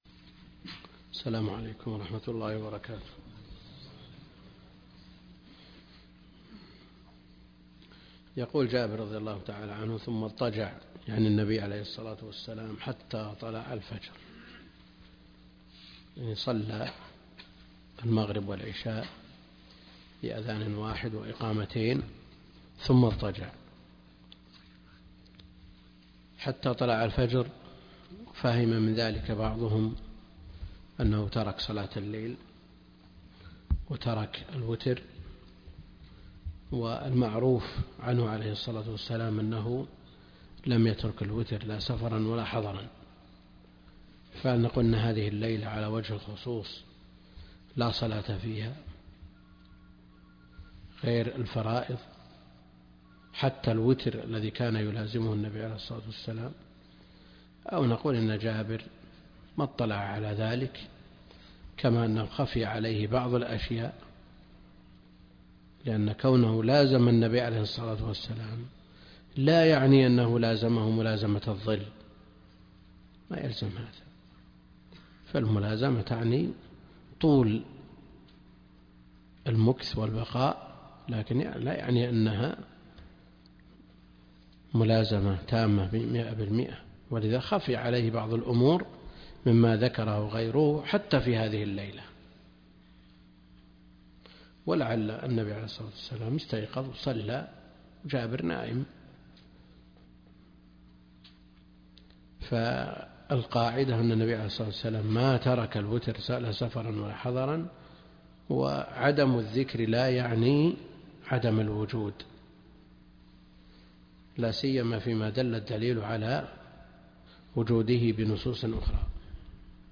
عنوان المادة الدرس (4) كتاب الحج من بلوغ المرام تاريخ التحميل الأثنين 29 يناير 2024 مـ حجم المادة 24.09 ميجا بايت عدد الزيارات 112 زيارة عدد مرات الحفظ 62 مرة إستماع المادة حفظ المادة اضف تعليقك أرسل لصديق